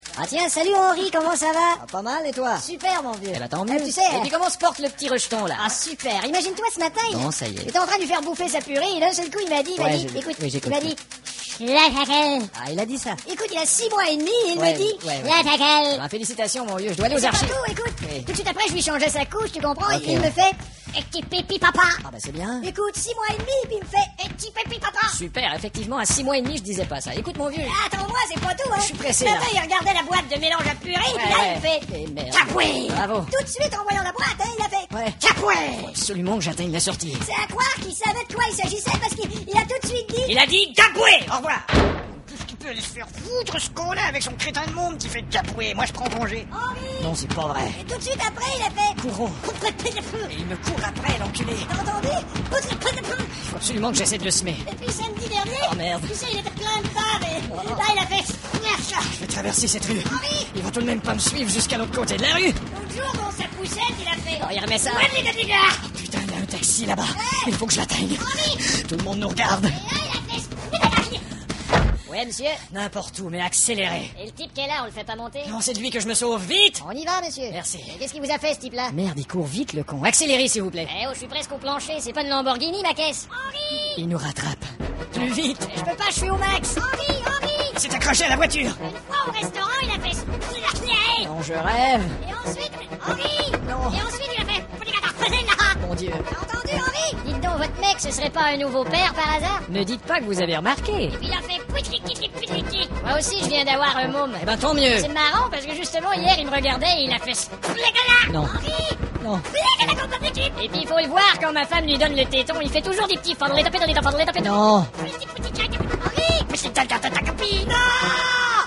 Dans cette rubrique j'ai fait une sélection des meilleurs sketches des 2 minutes du peuple.